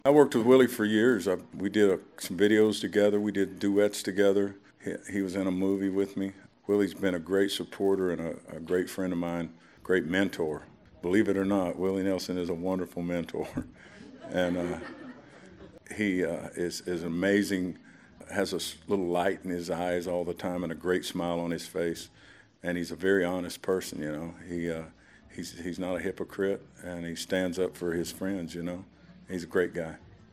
Backstage at the now defunct American Country Awards (ACAs) in 2010, Toby Keith talked about his mentor, friend and collaborator Willie Nelson with whom he had a No. 1 hit, “Beer For My Horses.”